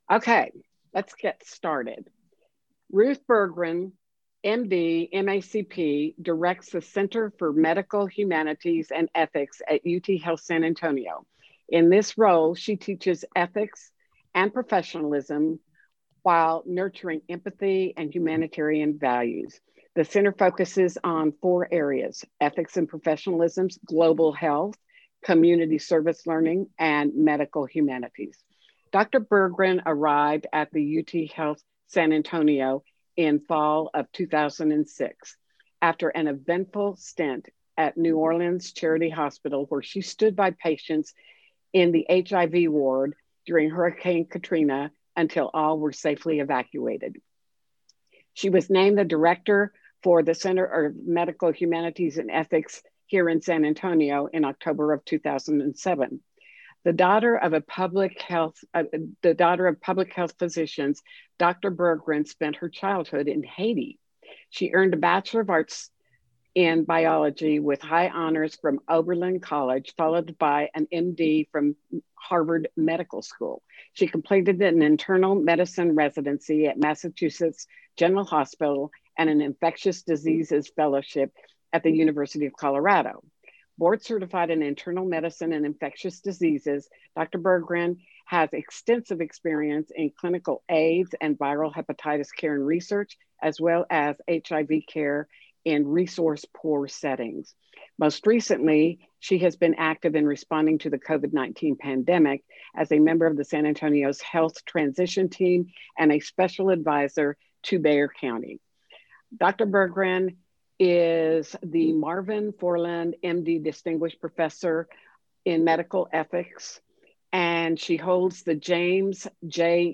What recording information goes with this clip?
Online Conference